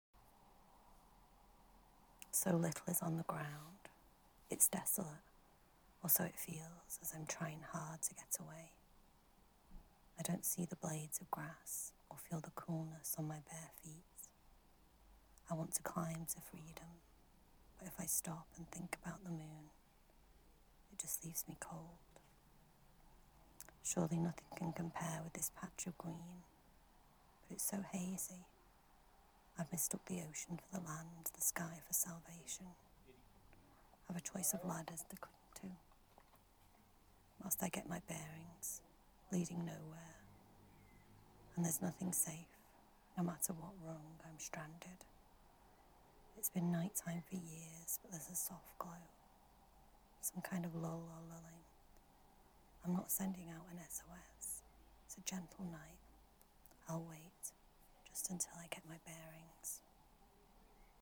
Gently spoken, loudly mused.